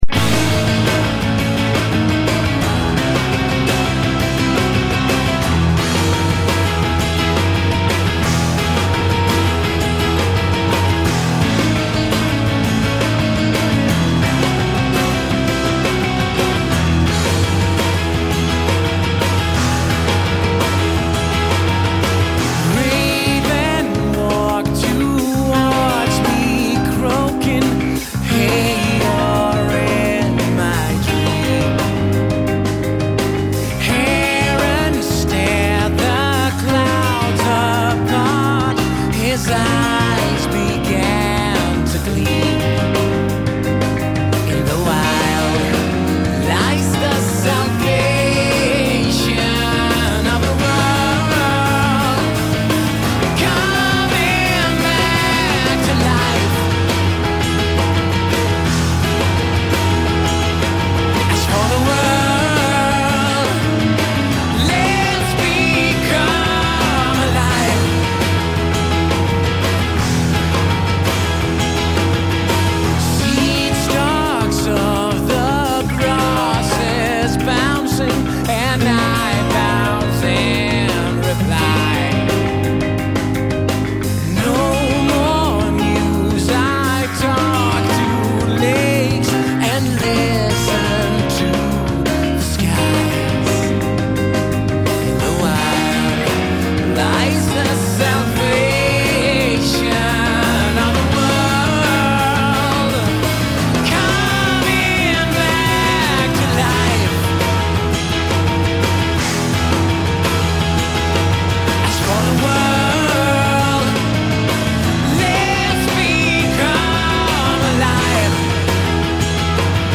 Swedish prog band